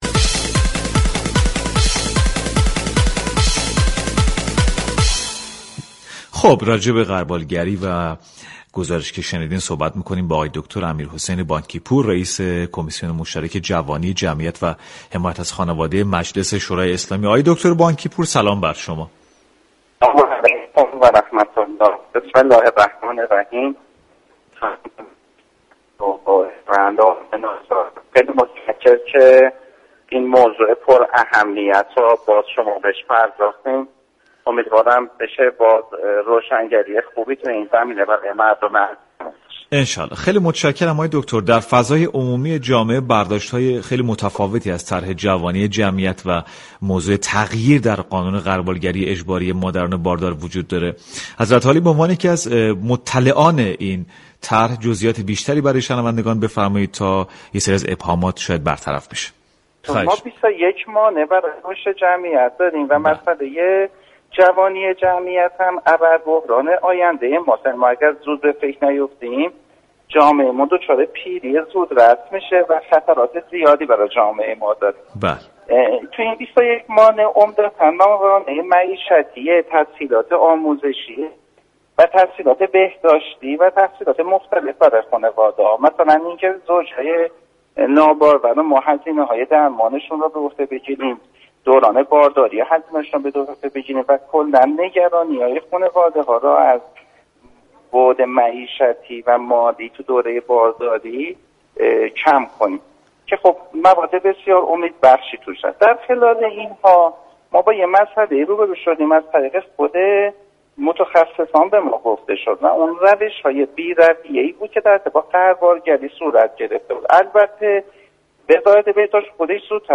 به گزارش پایگاه اطلاع رسانی رادیو تهران، امیرحسین بانكی پور رئیس كمیسیون مشترك جوانی جمعیت و حمایت از خانواده مجلس یازدهم در مورد طرح جوانی جمعیت و تغییر قانون غربالگری اجباری مادران باردار به سعادت آباد رادیو تهران گفت: 21 مانع برای رشد جمعیت داریم و اَبَر بحران آن همان جوانی جمعیت در آینده است و اگر به فكر نباشیم جامعه ما دچار پیری زودرس شده و در آینده با خطرات زیادی مواجه خواهیم بود.